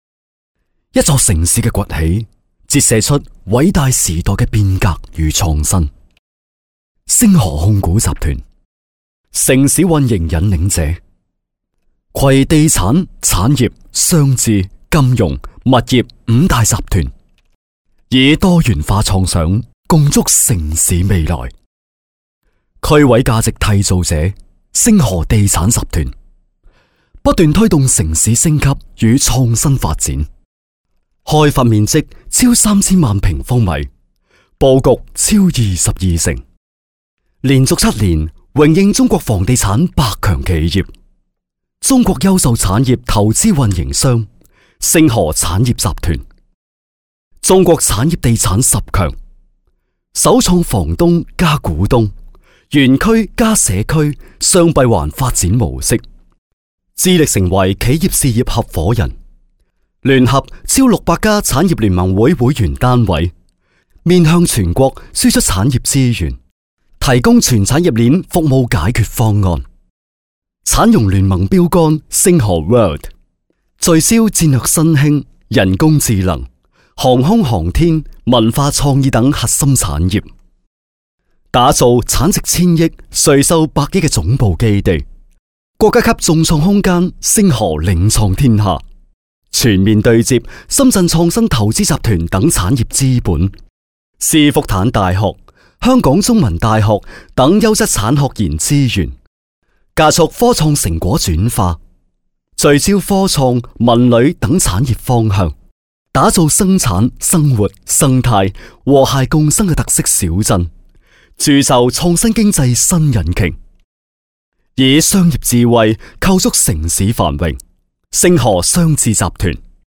粤语中年沉稳 、娓娓道来 、男专题片 、宣传片 、150元/分钟男粤35 专题片-顺德电商(带配乐)-粤语广式港式 沉稳|娓娓道来